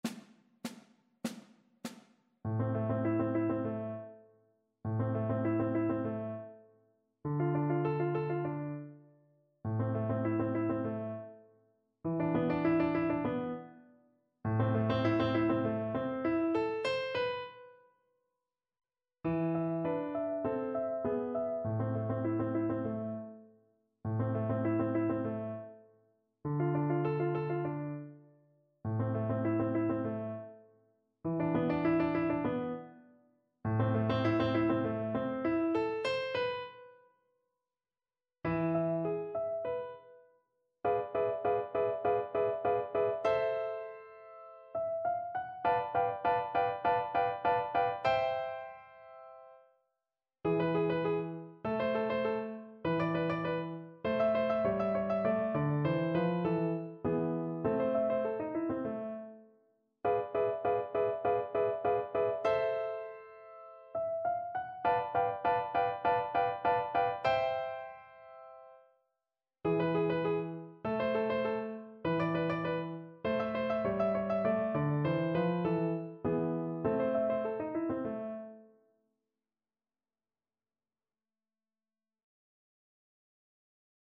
Burgmüller: Cicha skarga (na dwoje skrzypiec)
Symulacja akompaniamentu